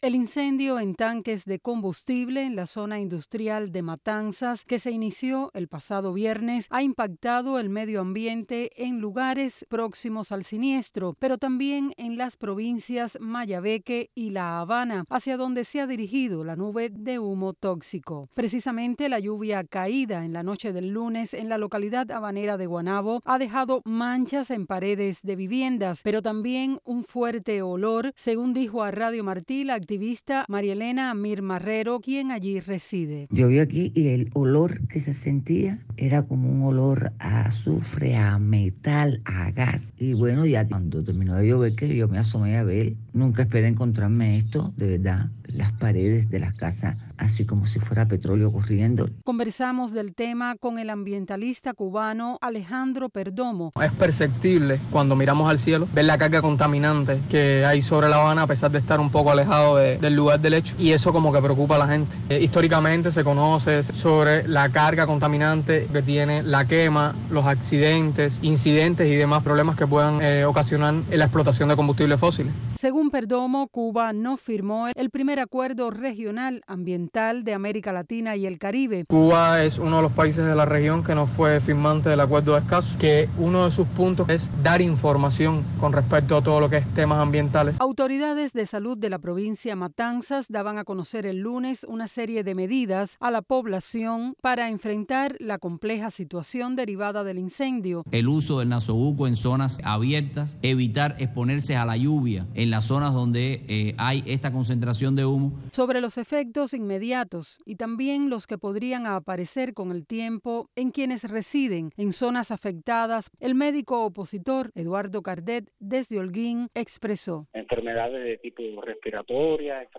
Conversamos del tema con el ambientalista cubano